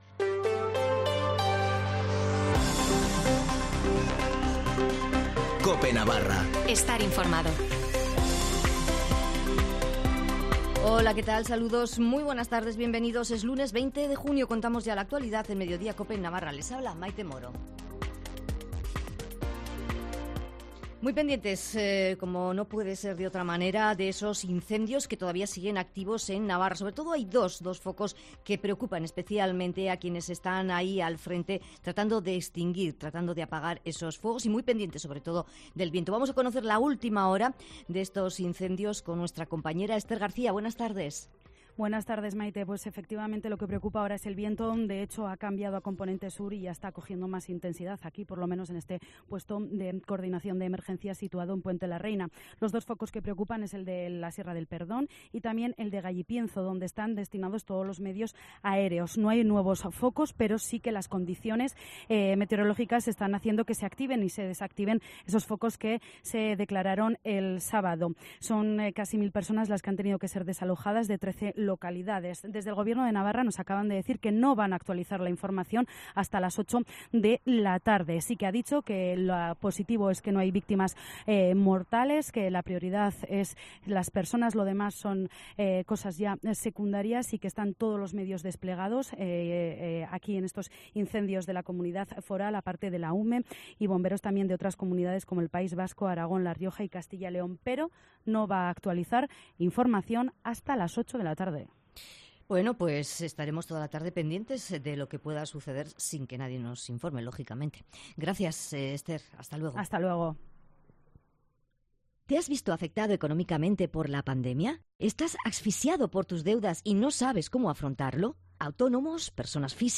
Informativo de las 14:20 en Cope Navarra (20/06/2022)